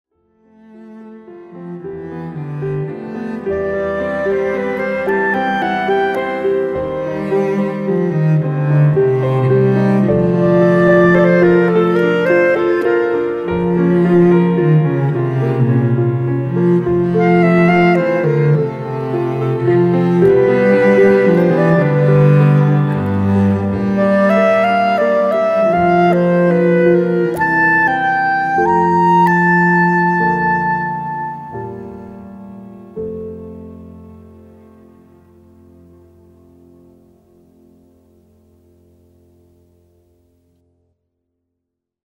und Computersounds, eingängig und atmosphärisch dicht.